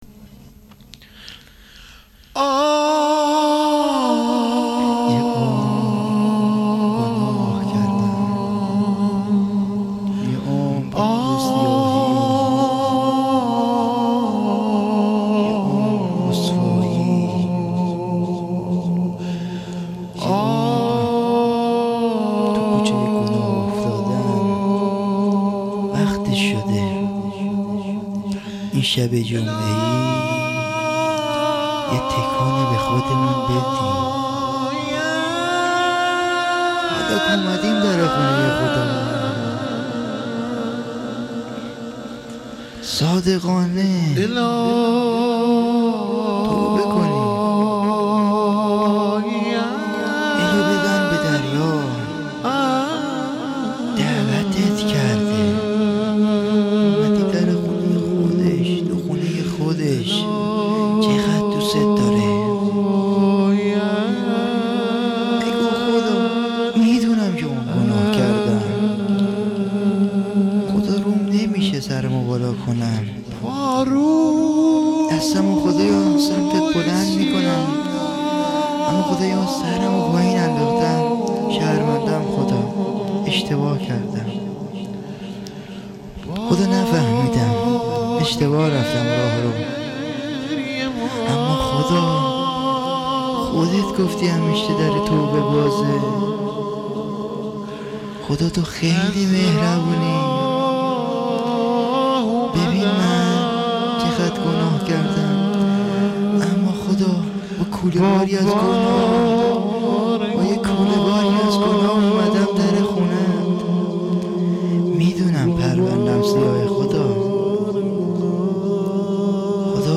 درد و دل با خدا و امام زمان عج الله دکلمه